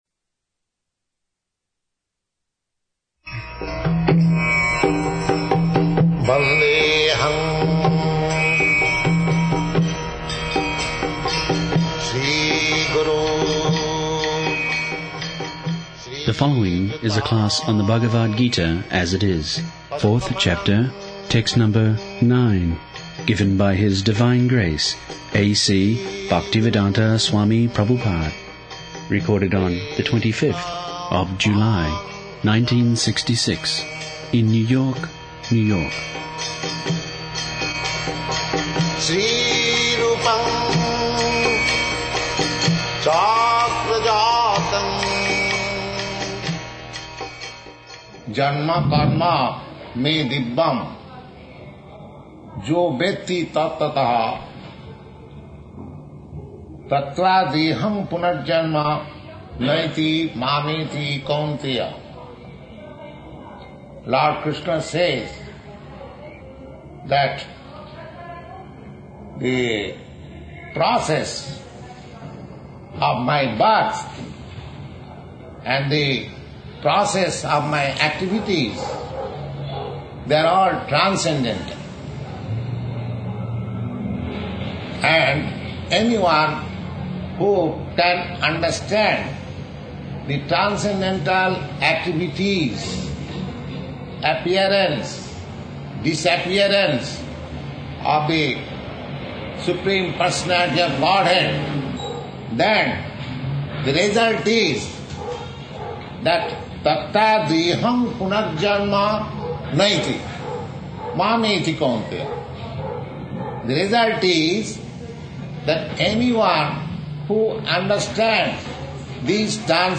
66/07/25 New York, Bhagavad-gita 4.9-11